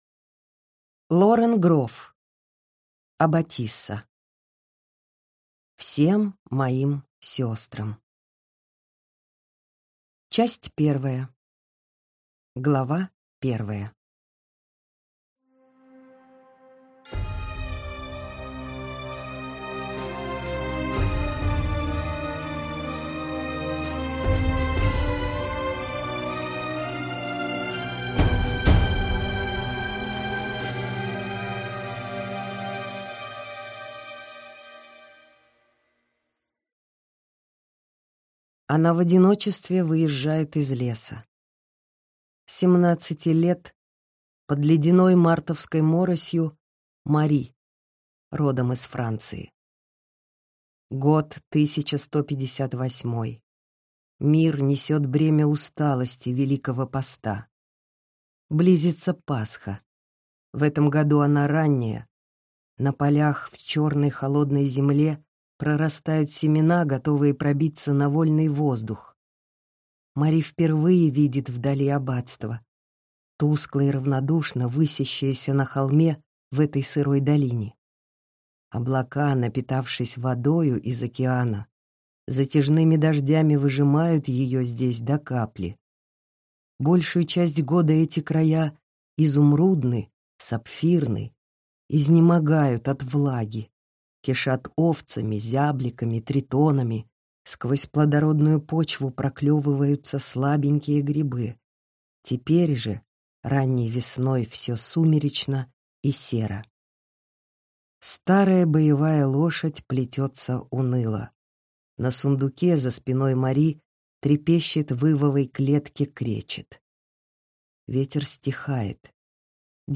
Аудиокнига Аббатиса | Библиотека аудиокниг